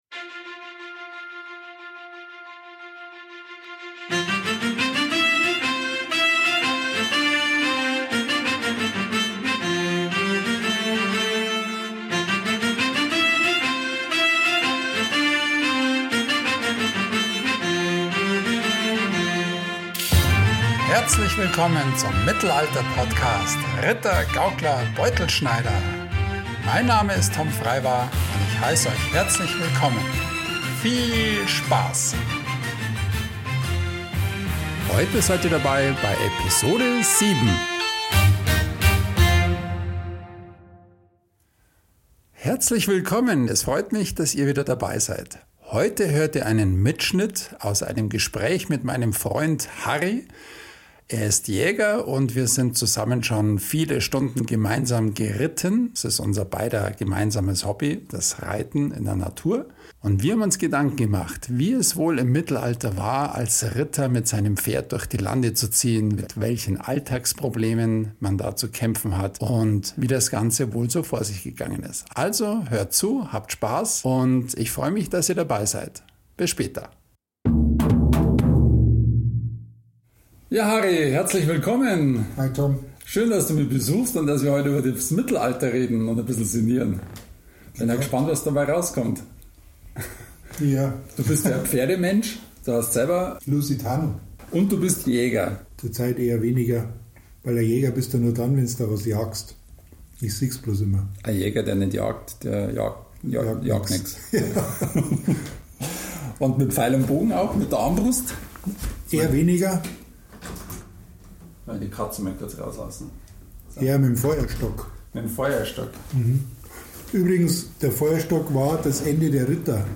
Wie war es wohl so im Mittelalter? Ein Gespräch zwischen Freunden ;0)